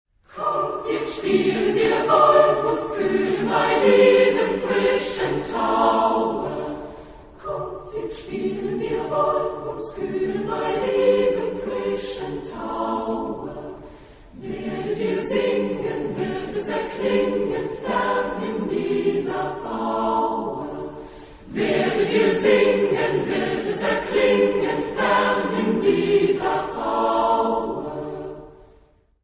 Genre-Style-Forme : Profane ; Renaissance ; Chanson
Caractère de la pièce : dansant
Type de choeur : SAAH  (4 voix mixtes )
Tonalité : fa majeur
interprété par Jugendchor der Landesschule Pforta
Réf. discographique : 6. Deutscher Chorwettbewerb, 2002